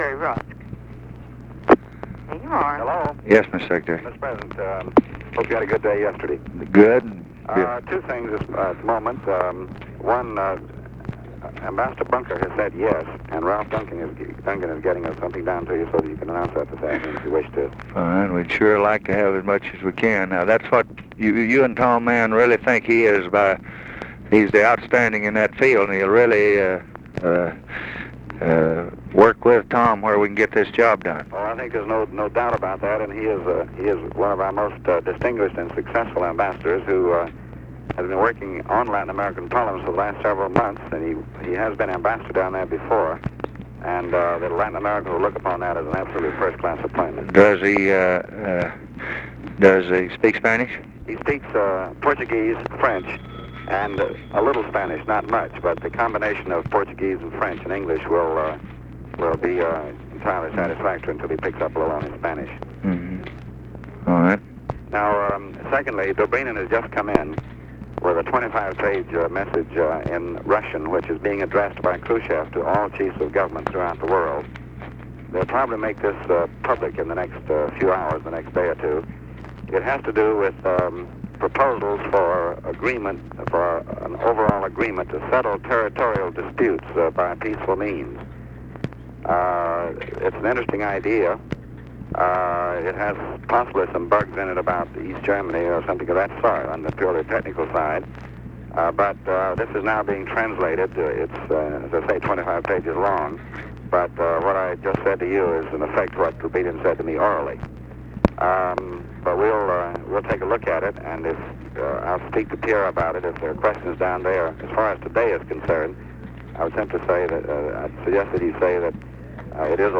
Conversation with DEAN RUSK, January 2, 1964
Secret White House Tapes